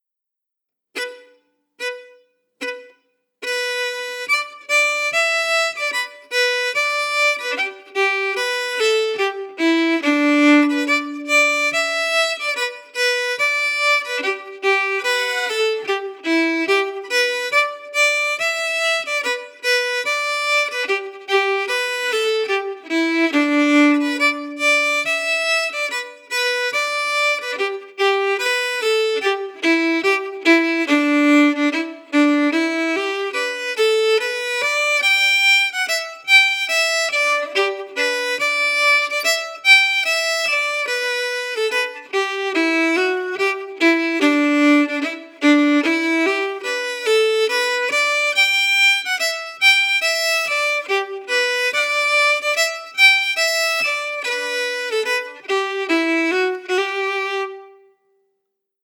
Key: G
Form: Reel
played slowly for learning